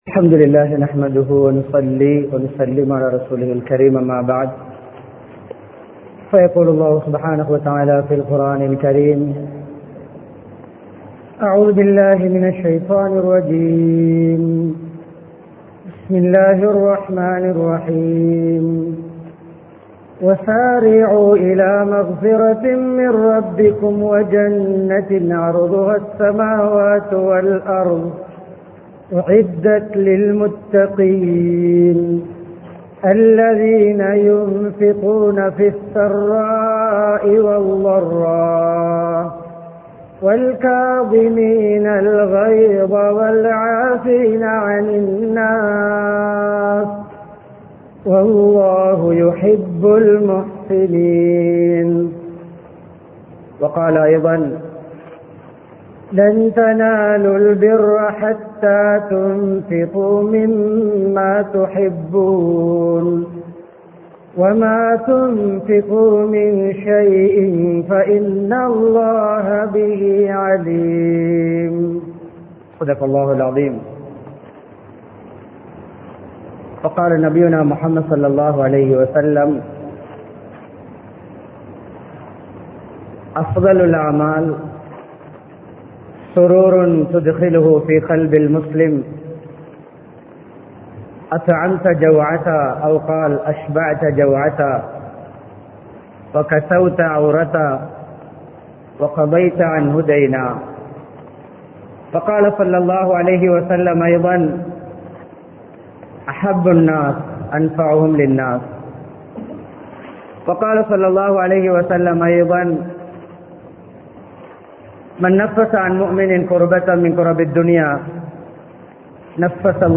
பிறரின் கஷ்டங்களை நீக்குவோம் | Audio Bayans | All Ceylon Muslim Youth Community | Addalaichenai
Panadura, Pallimulla Rawulathul Asfiya Jumuah Masjith